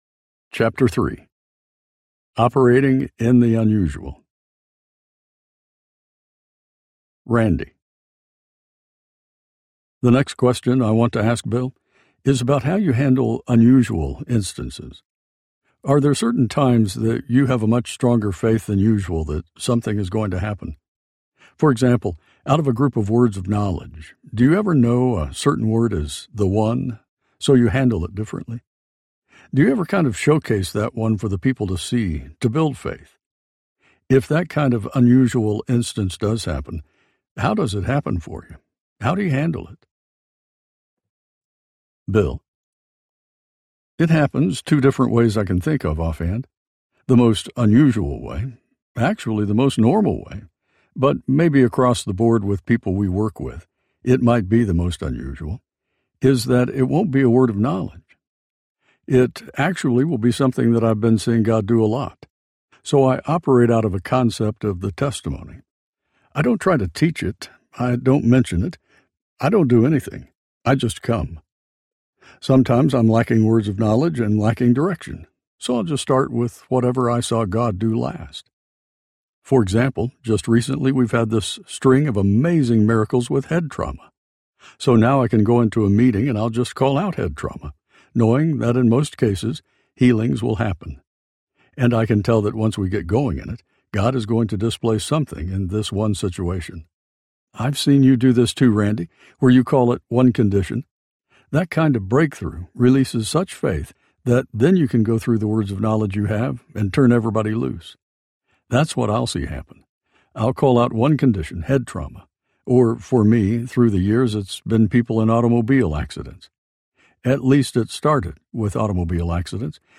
Anointed to Heal Audiobook
4.27 Hrs. – Unabridged